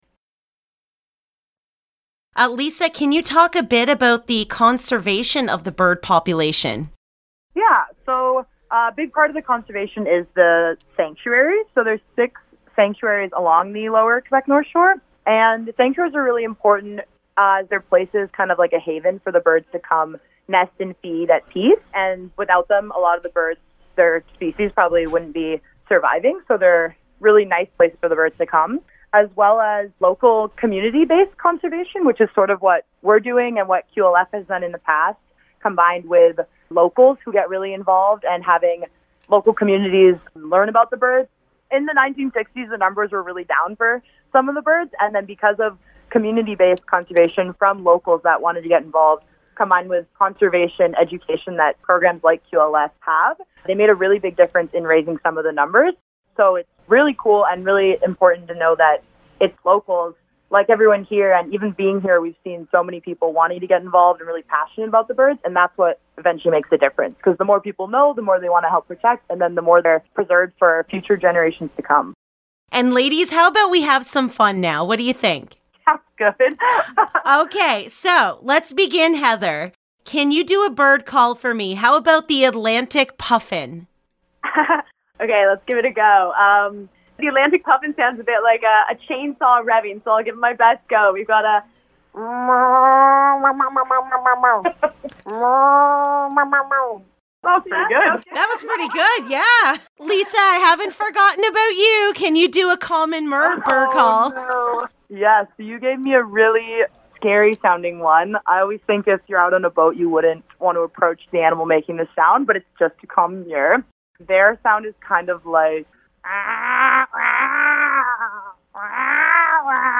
LOCAL NEWS - AUGUST 3, 2021 - Learning about our local seabird population